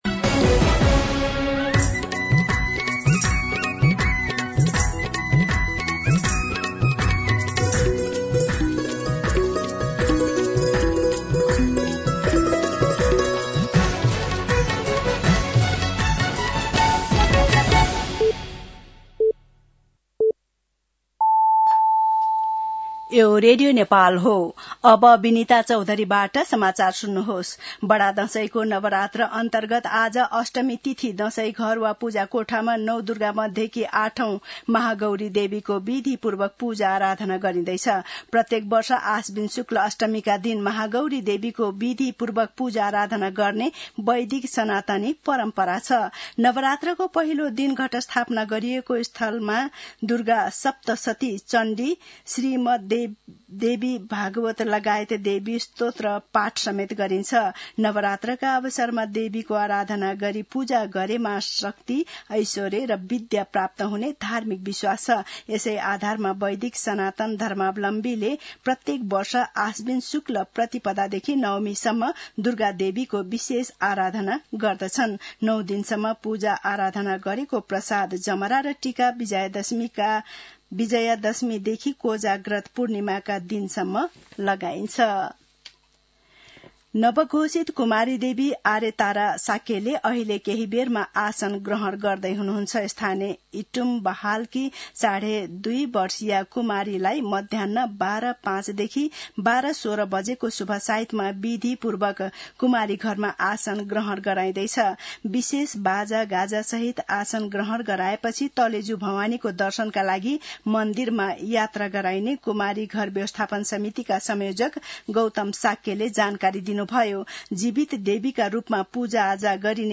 मध्यान्ह १२ बजेको नेपाली समाचार : १४ असोज , २०८२